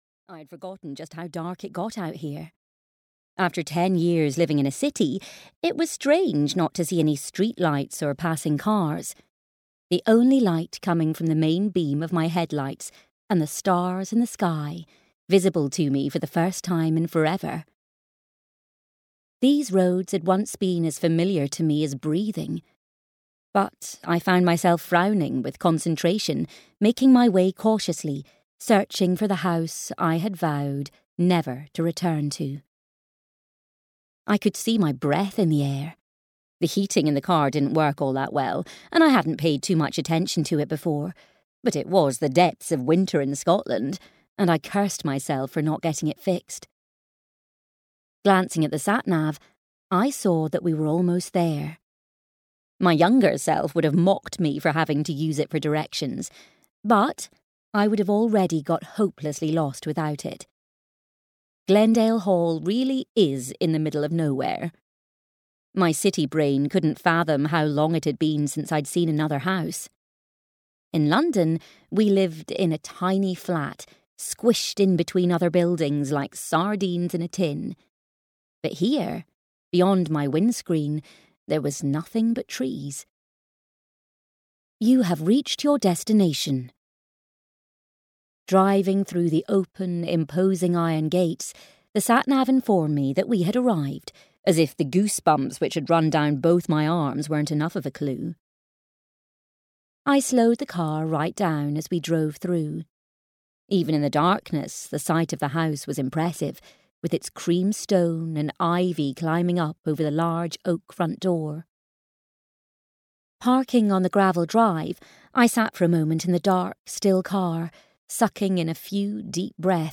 Coming Home to Glendale Hall (EN) audiokniha
Ukázka z knihy